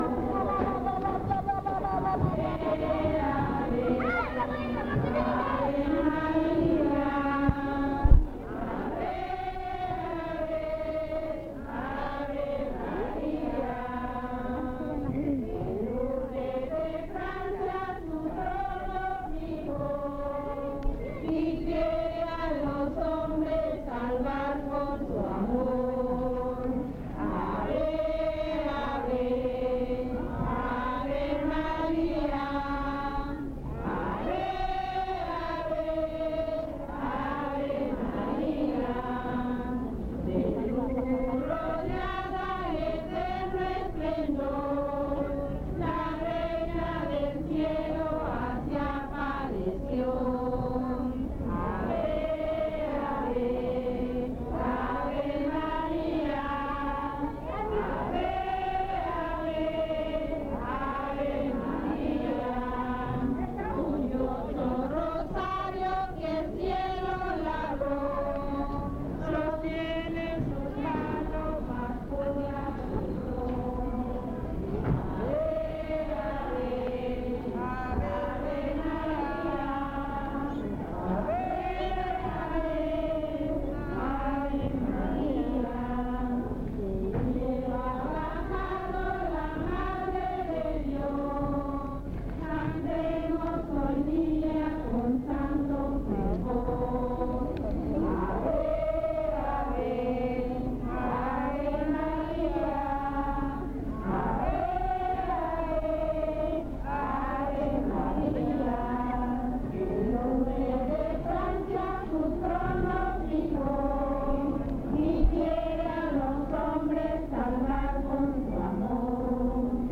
Fiesta de Santiago Tuxtla : investigación previa